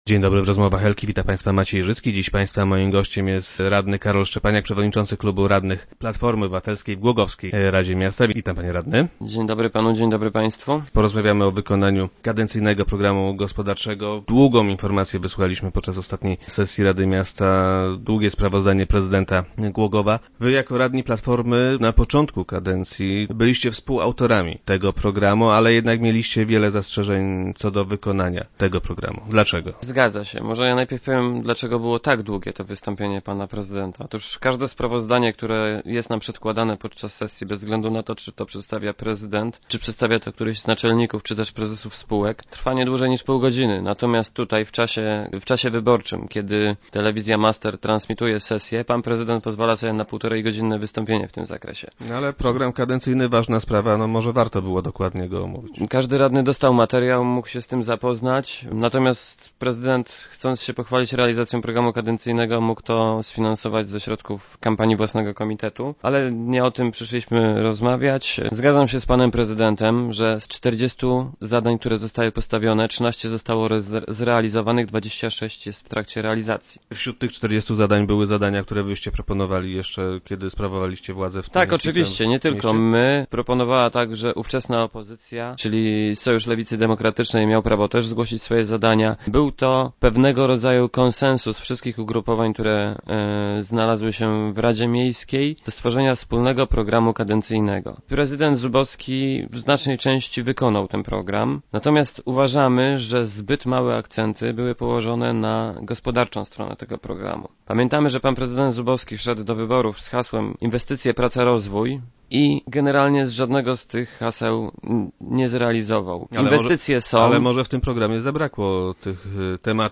Radni PO twierdzą jednak, że zapomniano o gospodarce. Gościem Rozmów Elki był Karol Szczepaniak, wiceprzewodniczący klubu radnych PO.
Jak powiedział na radiowej antenie radny Szczepaniak, Głogów nie ma w tej chwili nic do zaoferowania młodym ludziom, którzy nie czekali na darmowe mieszkania, lecz zaciągnęli kredyty by je sobie kupić.